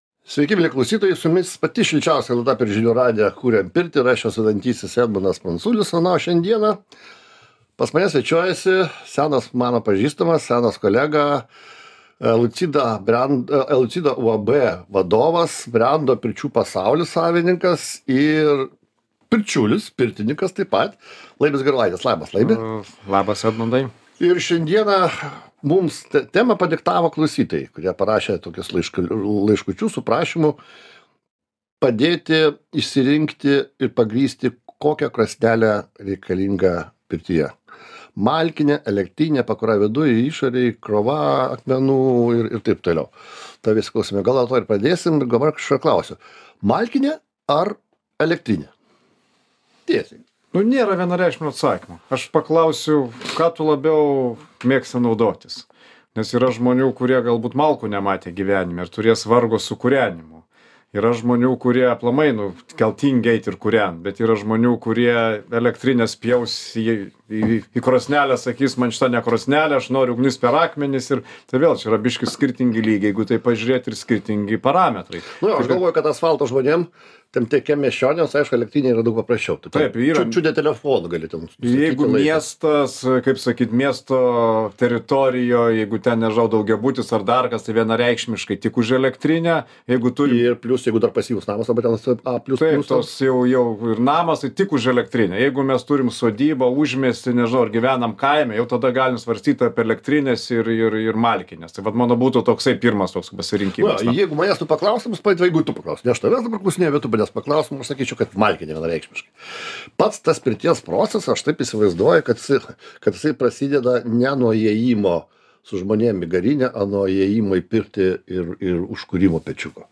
Kaip teisingai išsirinkti krosnelę – galingumas, tipas, akmenų krova ir kiti ypatumai. Pokalbis